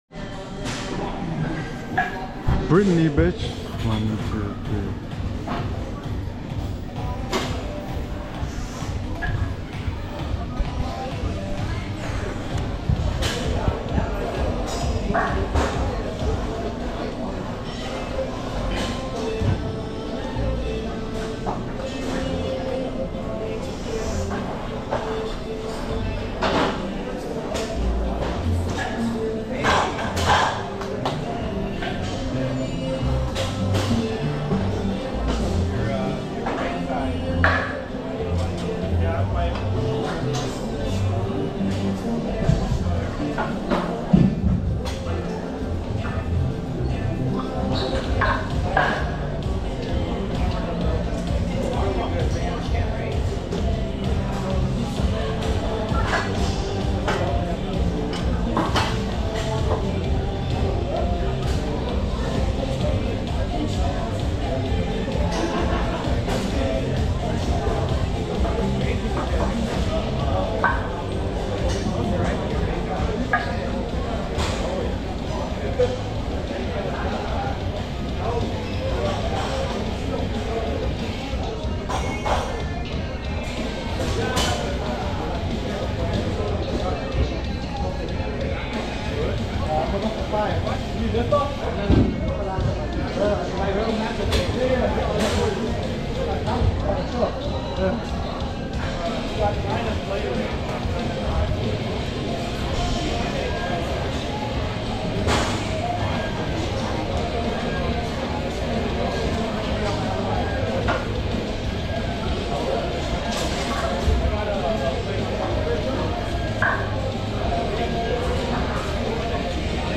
GYM ASMR